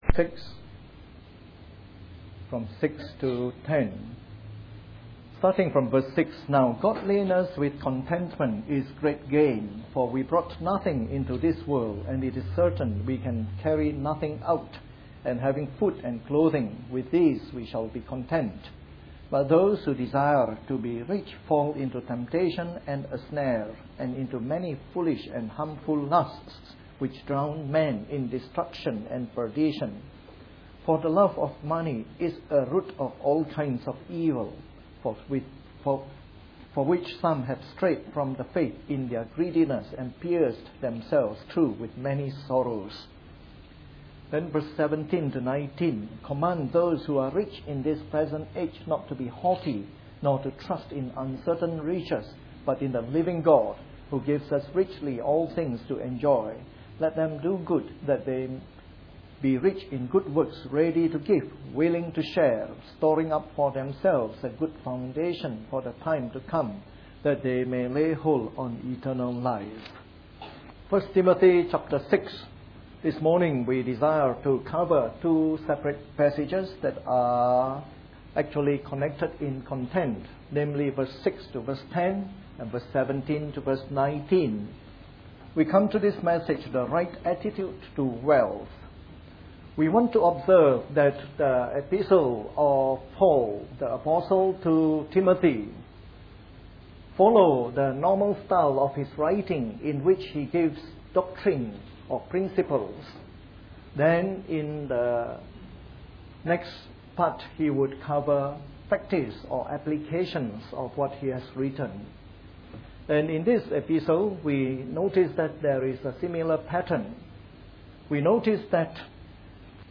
A sermon in the morning service from our series on 1 Timothy.